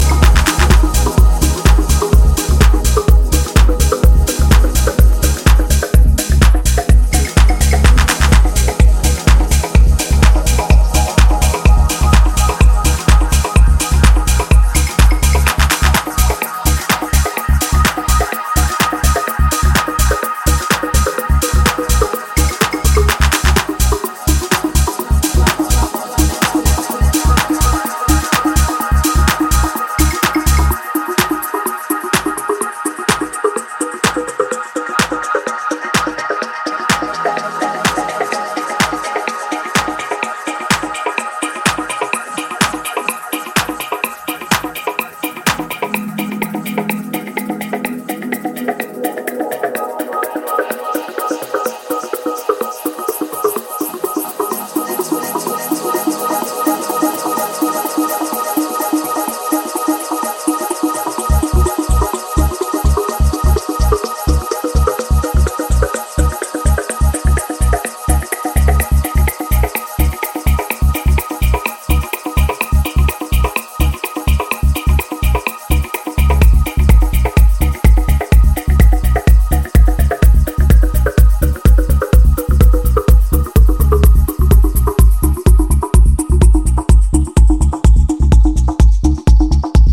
a loopy tool with frills
Disco House